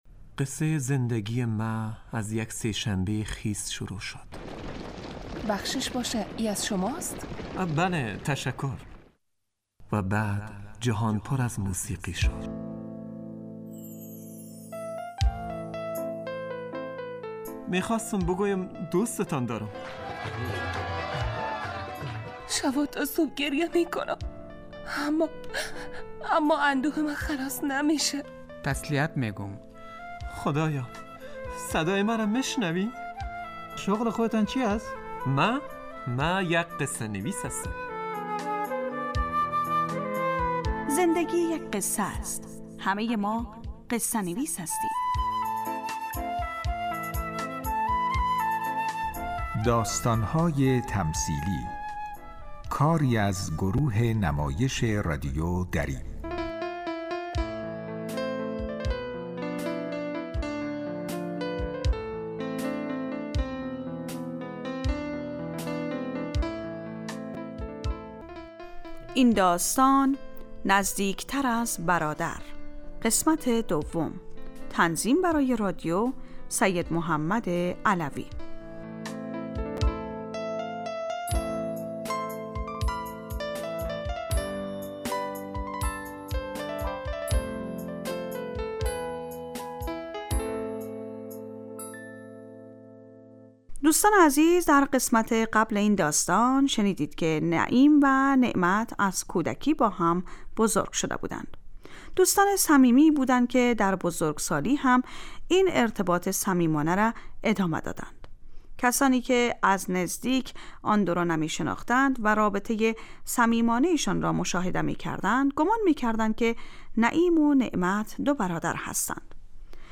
داستان های تمثیلی یک برنامه 15 دقیقه ای در قالب نمایش رادیویی می باشد که همه روزه به جز جمعه ها از رادیو دری پخش می شود موضوع اکثر این نمایش ها پرداختن به...